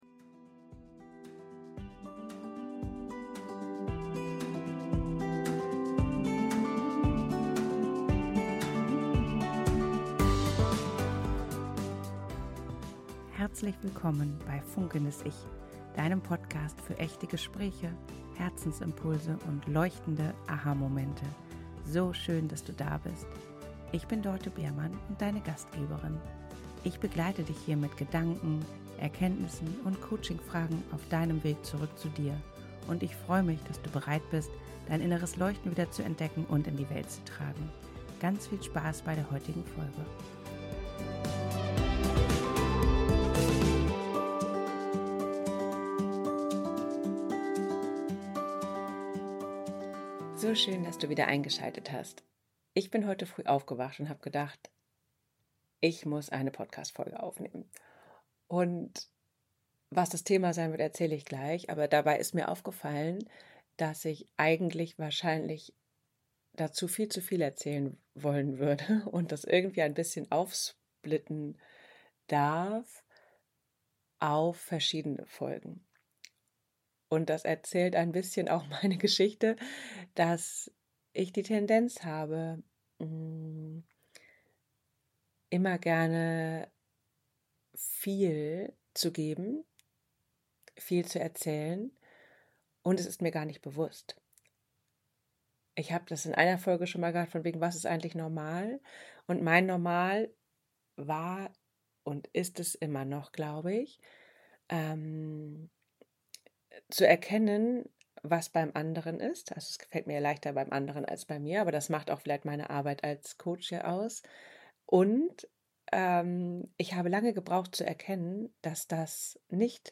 In dieser sehr persönlichen Solo-Folge teile ich ein Beispiel aus meinem Alltag: Mein „Sofa-Moment“!